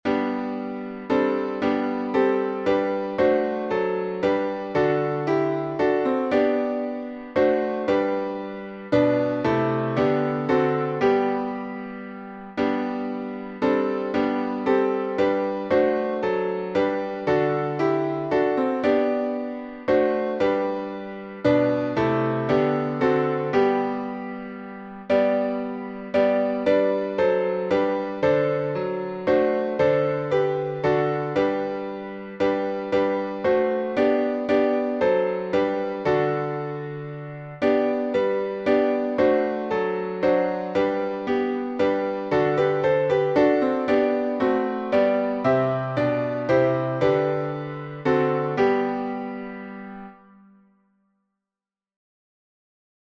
Key signature: G major (1 sharp) Time signature: 3/4